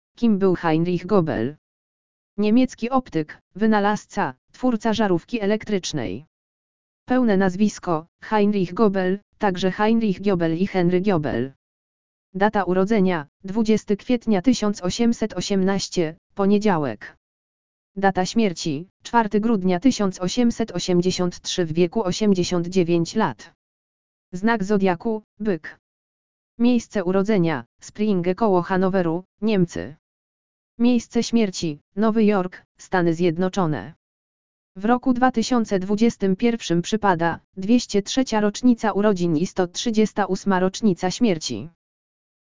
audio_lektor_urodziny_heinricha_gÖbela.mp3